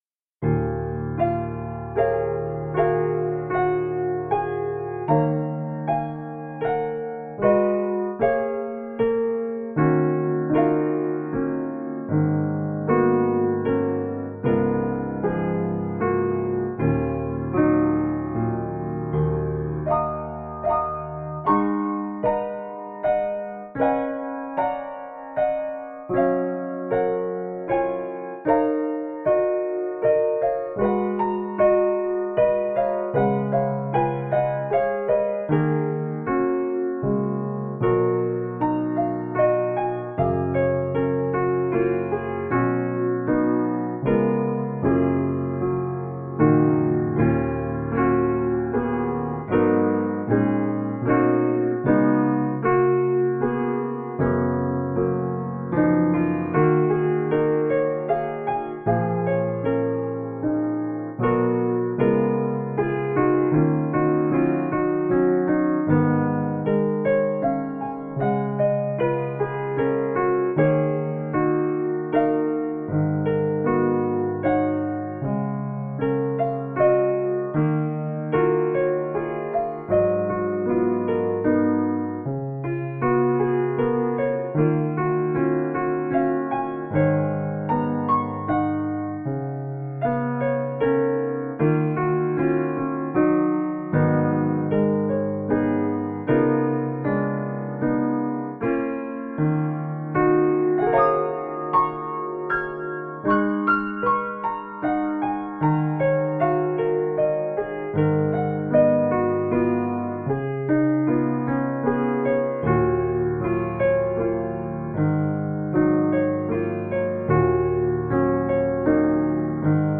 Magical, heart inspiring music.